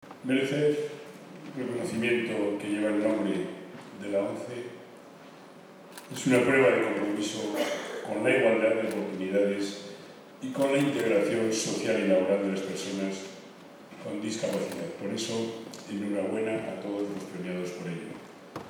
Por su parte, el presidente riojano, Ceniceros dio la enhorabuena a todos los premiados con un reconocimiento que -dijo- lleva el nombre de la ONCE y, por tanto, es prueba de su